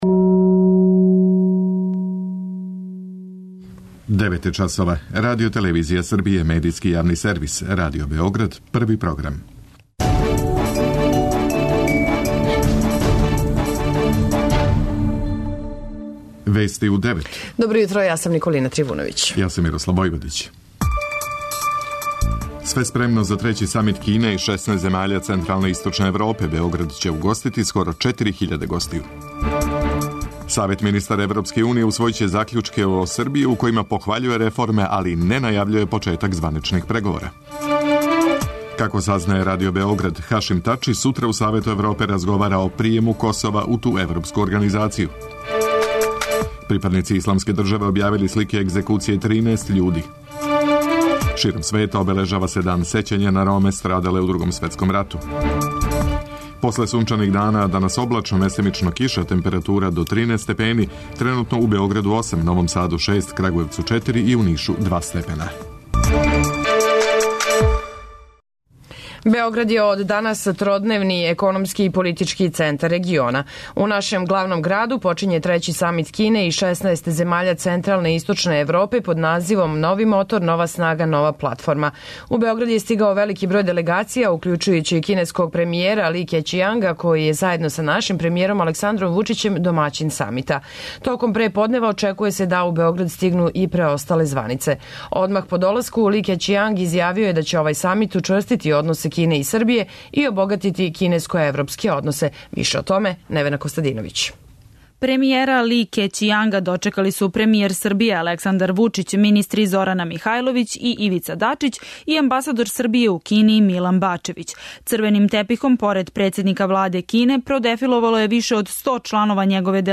После сунчаних дана, данас облачно, местимично киша, температура до 13 степени. преузми : 10.29 MB Вести у 9 Autor: разни аутори Преглед најважнијиx информација из земље из света.